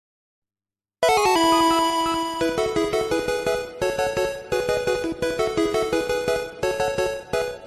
Play, download and share Pkmon win original sound button!!!!
pkmon-win-follow-alert.mp3